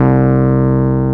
RHODES2H C2.wav